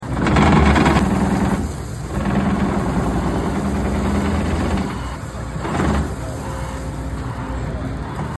Kobelco excavator demonstrating the Rototilt